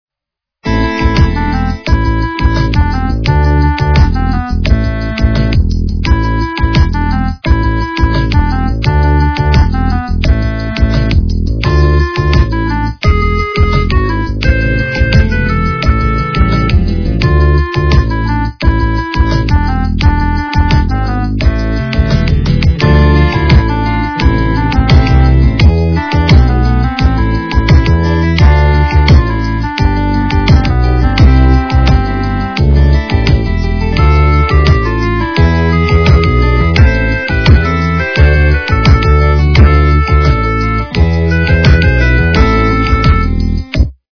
- русская эстрада
качество понижено и присутствуют гудки.
полифоническую мелодию